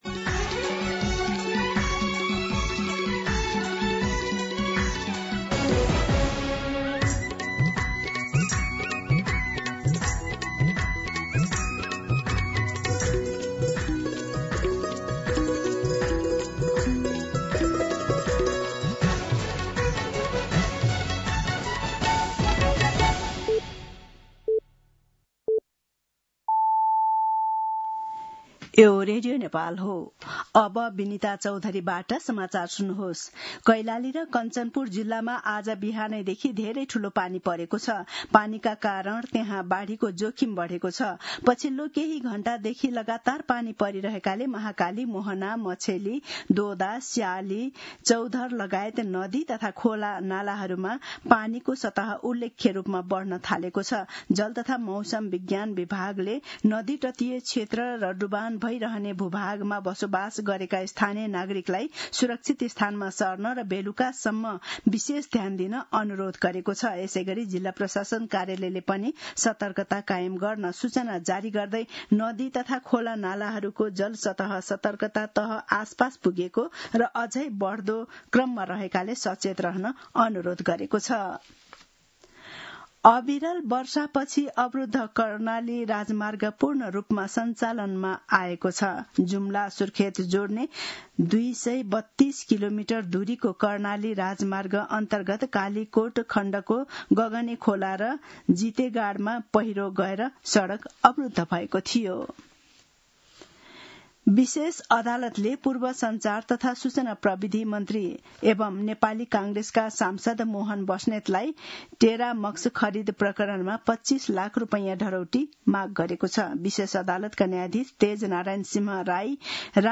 दिउँसो १ बजेको नेपाली समाचार : १६ असार , २०८२